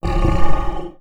MONSTERS_CREATURES
MONSTER_Growl_Subtle_02_mono.wav